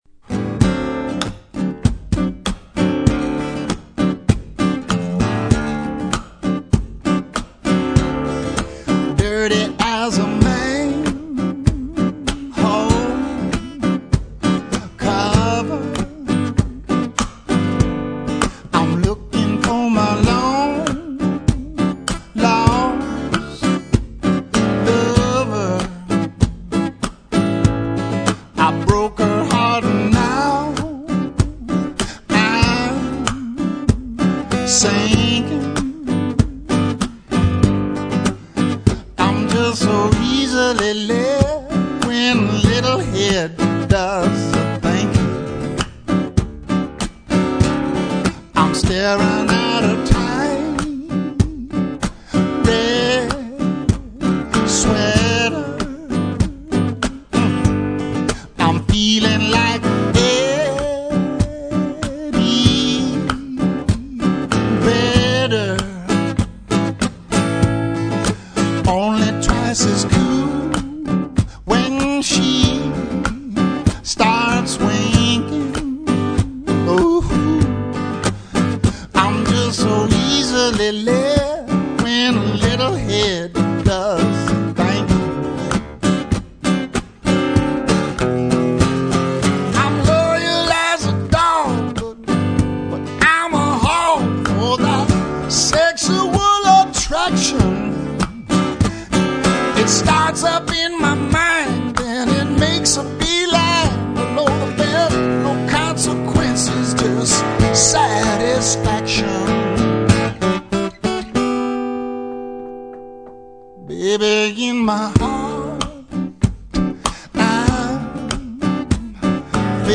Acoustic